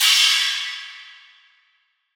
Crashes & Cymbals
DDW4 CRASH 1.wav